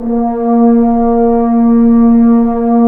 Index of /90_sSampleCDs/Roland L-CD702/VOL-2/BRS_F.Horns 1/BRS_FHns Ambient
BRS F.HRNS0A.wav